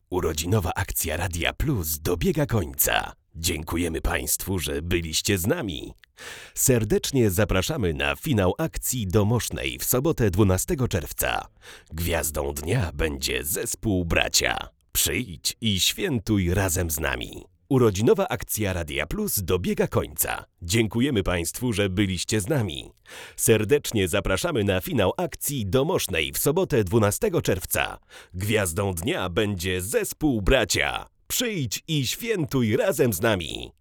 Jest czysto, gładko i wyraźnie, a jednocześnie naturalnie.